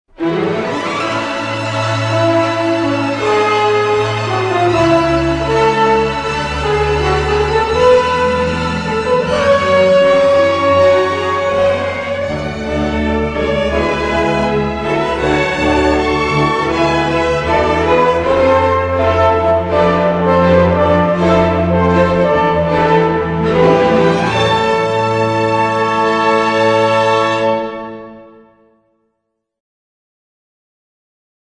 Film Musik - Score